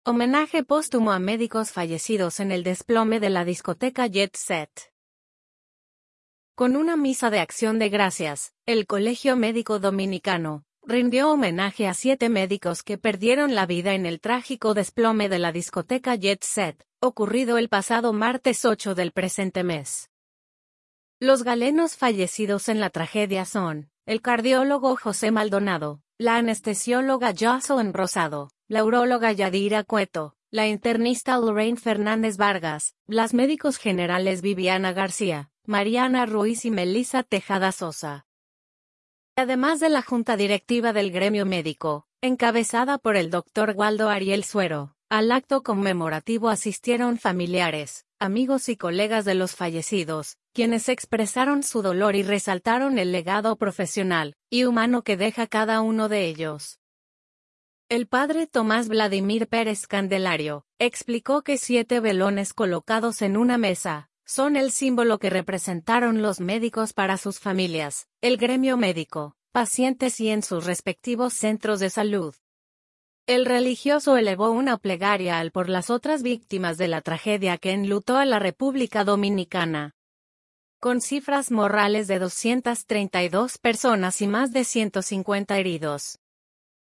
Con una misa de acción de gracias, el Colegio Médico Dominicano, rindió homenaje a siete médicos que perdieron la vida en el trágico desplome de la discoteca Jet Set, ocurrido el pasado martes 8 del presente mes.